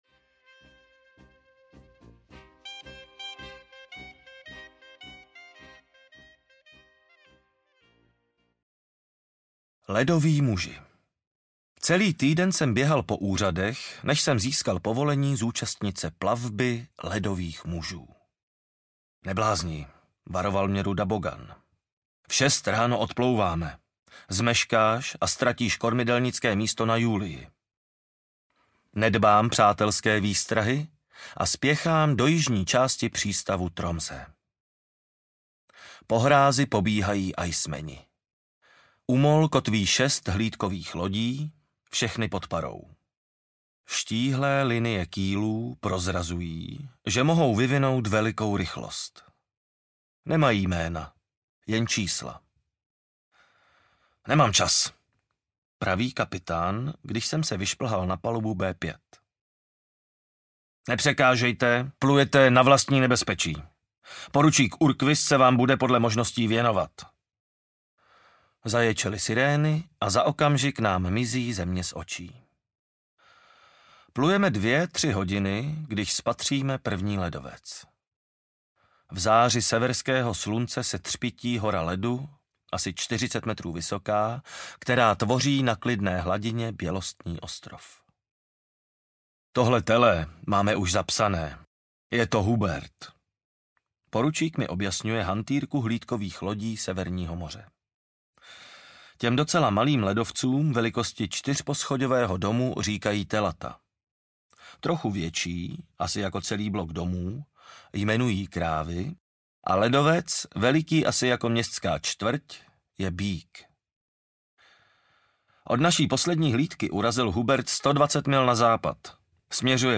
Ukázka z knihy
na-vlnach-odvahy-a-dobrodruzstvi-audiokniha